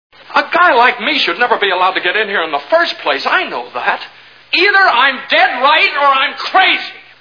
Mr Smith Goes to Washington Movie Sound Bites